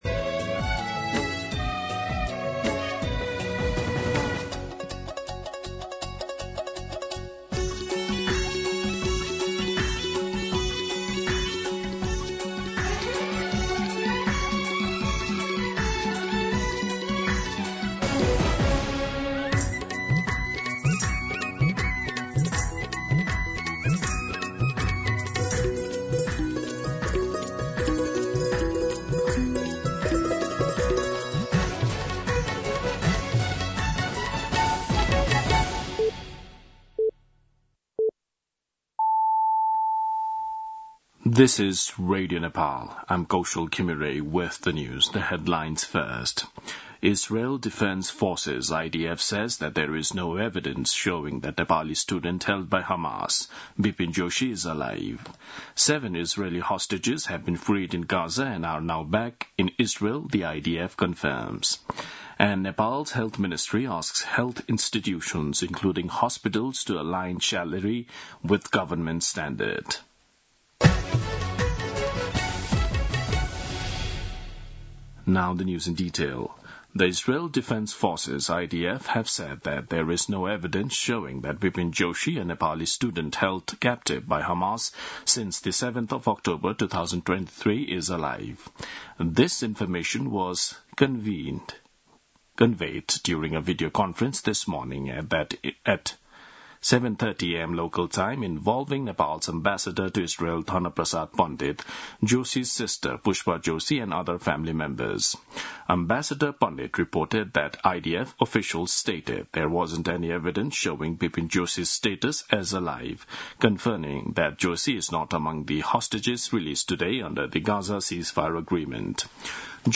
दिउँसो २ बजेको अङ्ग्रेजी समाचार : २७ असोज , २०८२
2-pm-English-News-3.mp3